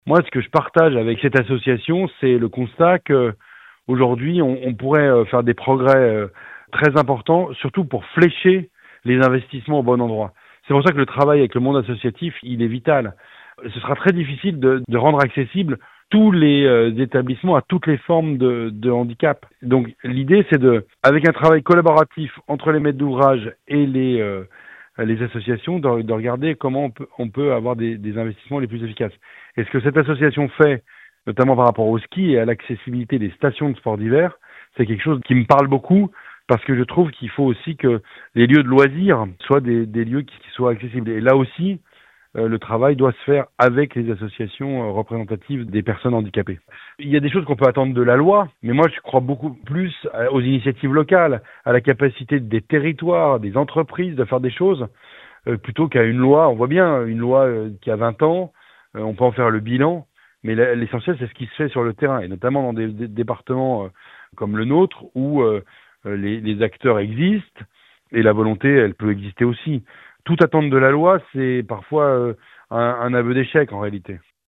ITC Loïc Hervé-Anniversaire loi accessibilité pour tous (1’’14)